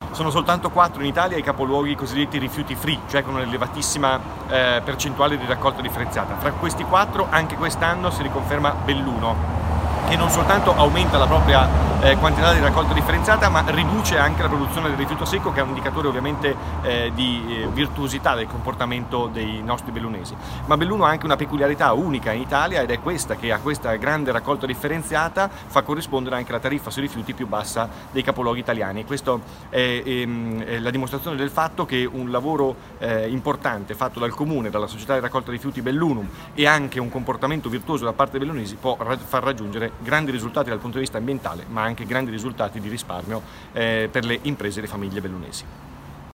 IL SINDACO DI BELLUNO JACOPO MASSARO